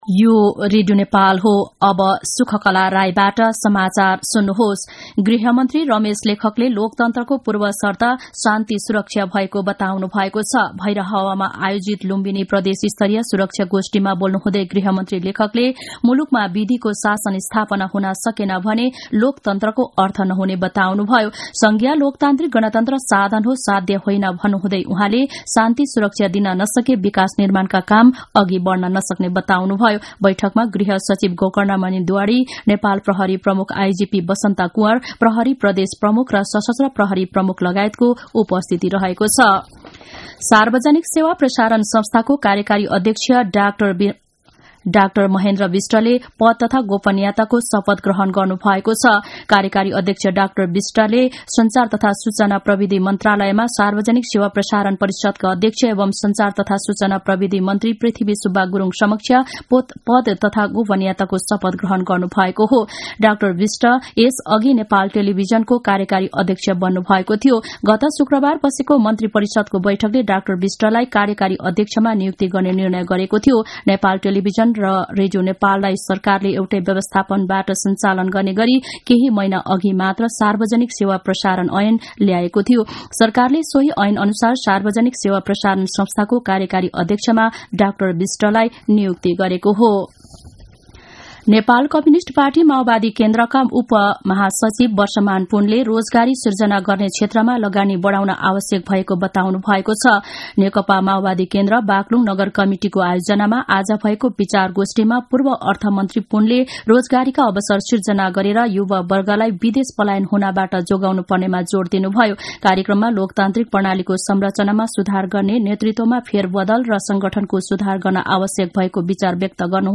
मध्यान्ह १२ बजेको नेपाली समाचार : ३ पुष , २०८१
12-am-nepali-news-1-13.mp3